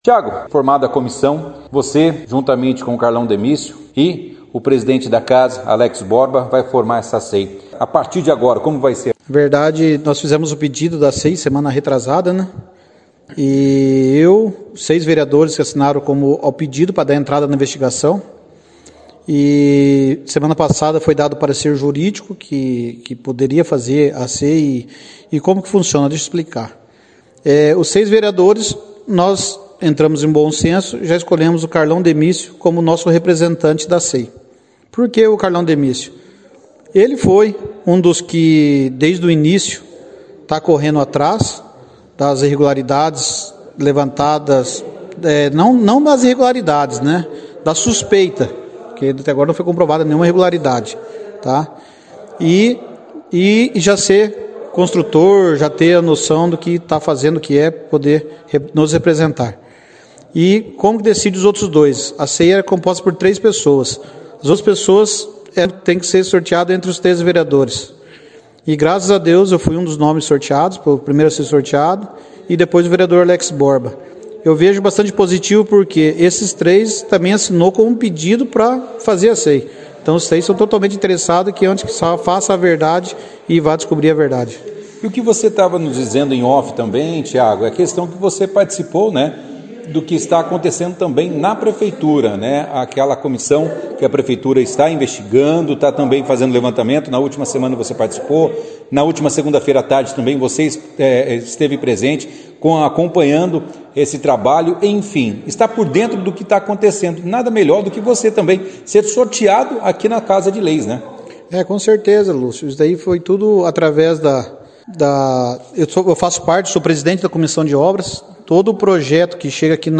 No último dia 17/04, ocorreu a décima sessão ordinária do ano legislativo de 2023 no plenário da Câmara Municipal de Bandeirantes. Na pauta, foram votados diversos requerimentos apresentados pelos vereadores na sessão anterior, além dos projetos de Lei do Executivo.